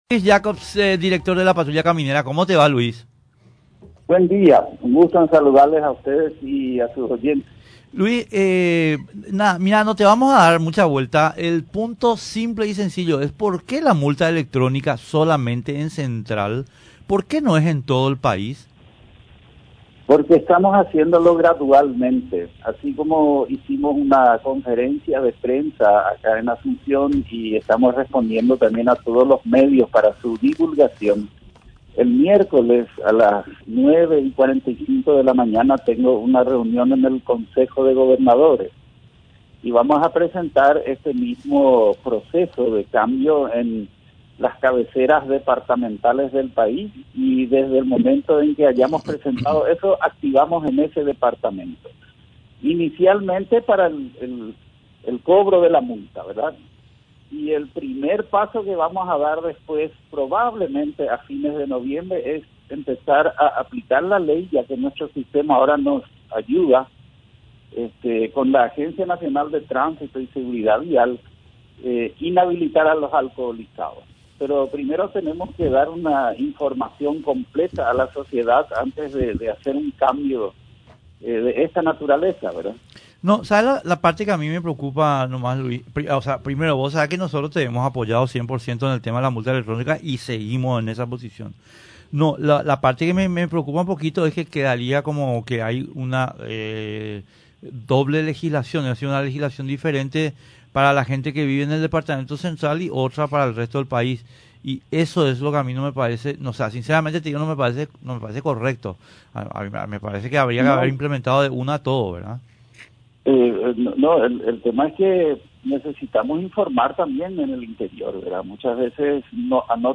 En comunicación con la 730 AM, el director de la Patrulla Caminera, Luis Christ Jacobs, anunció que en el  departamento Central, las emisiones de comprobante de ingreso de forma manual, en concepto de cobros de multa por infracciones a las normas de tránsito están prohibidas.